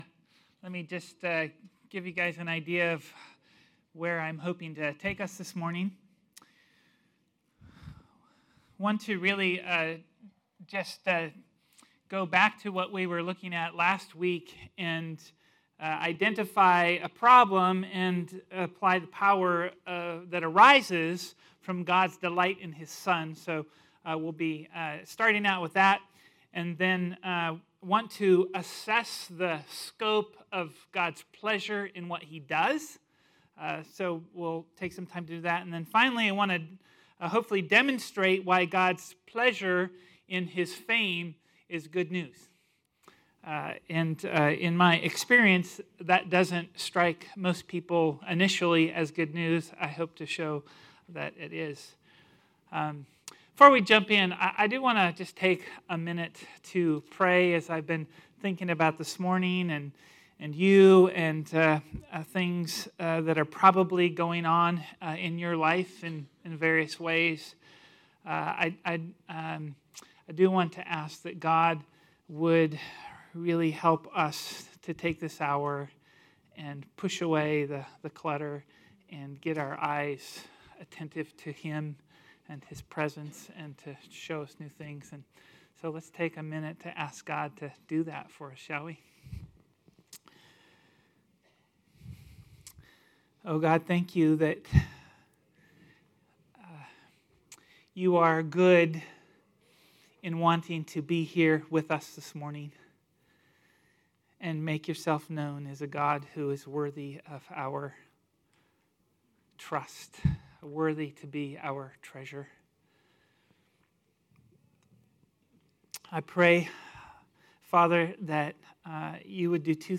Type: Sunday School